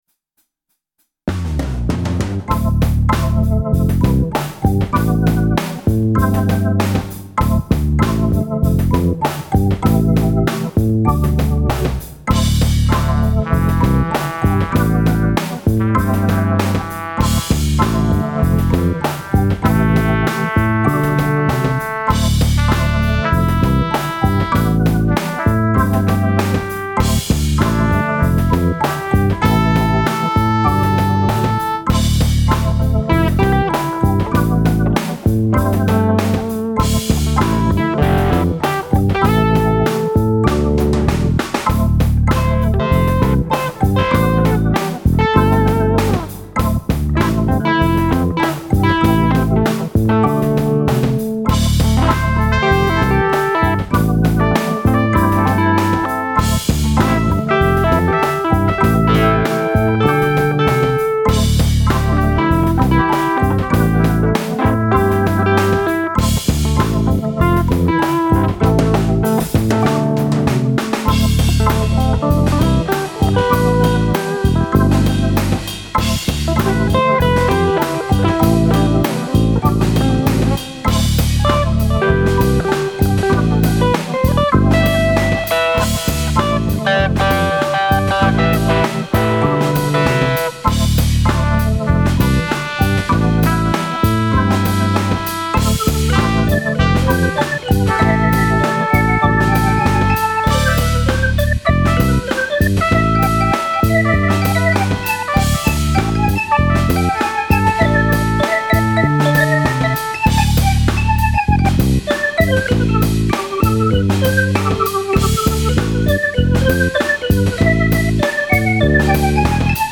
Die Solo-Gitarrenspuren sind mit dem Mittelfinger der rechten Hand gezupft, weil das zu dem Zeitpunkt, das einzige war, was ich am rechten Arm noch einigermassen kontrollieren konnte.